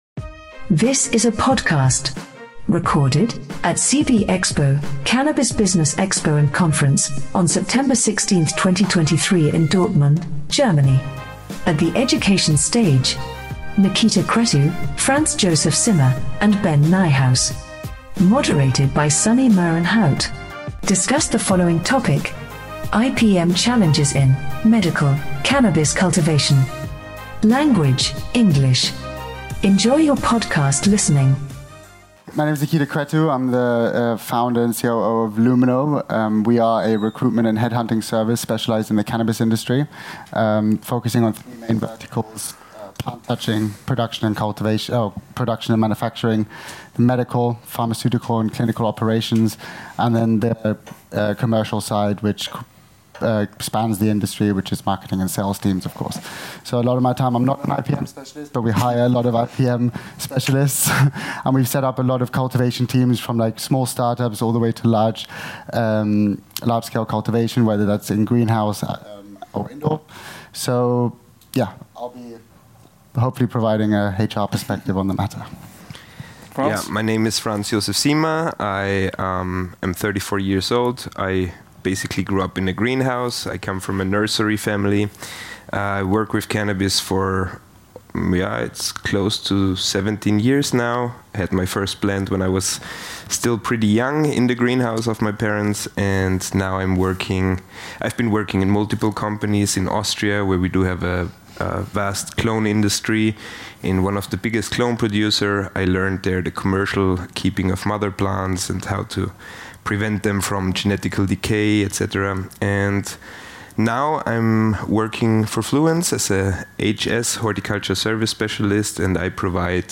Don't miss this opportunity to gain valuable insights into the realm of medical cannabis cultivation, as our panel of experts share their experiences in navigating the complexities of Integrated Pest Management (IPM) and the new threat of Hop Latent Viroid (HLVD).